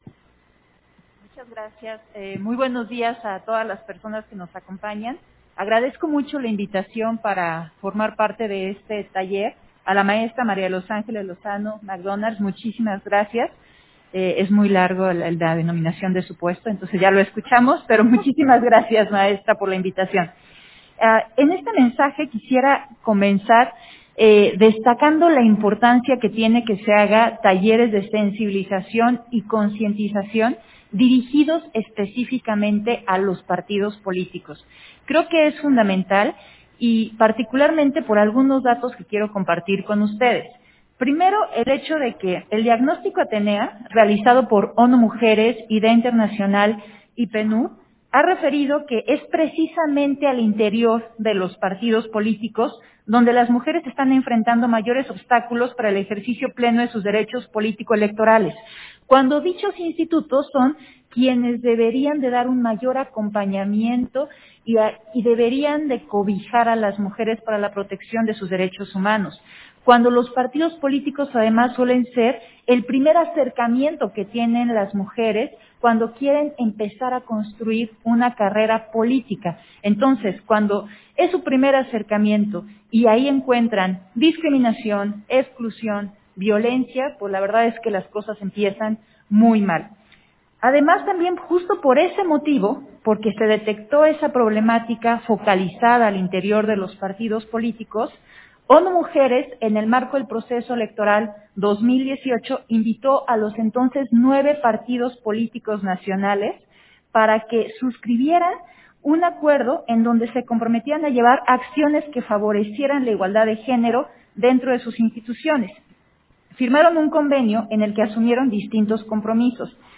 Intervenciones de Dania Ravel, en la mesa: La problemática de la violencia política contra las mujeres en razón de género al interior de los partidos políticos, del taller de sensibilización en materia de VPMRG